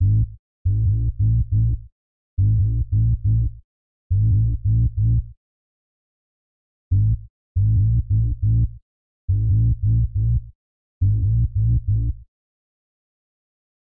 肮脏的Dubstep基调Eb Min139 BPM
描述：我的自定义Serum预设。自定义循环。
标签： 139 bpm Dubstep Loops Bass Wobble Loops 2.32 MB wav Key : D
声道立体声